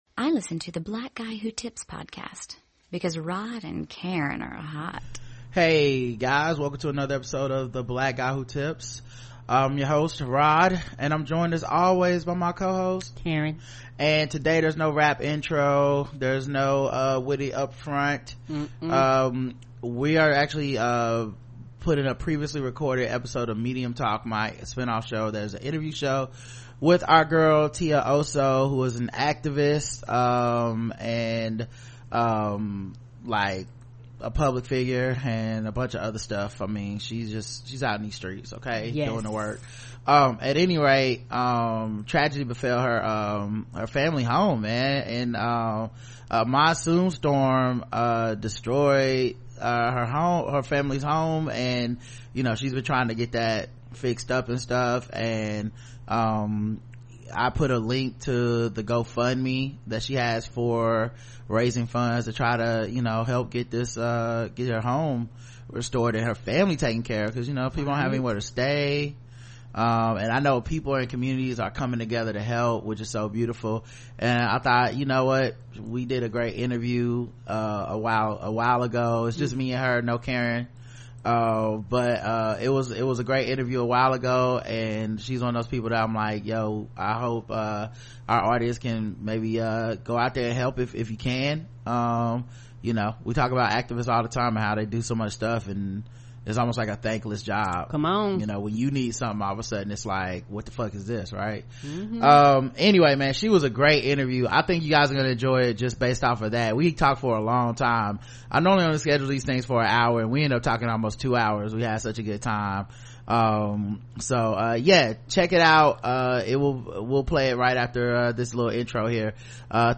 This was a lovely conversation that went on so long but passed by so quick.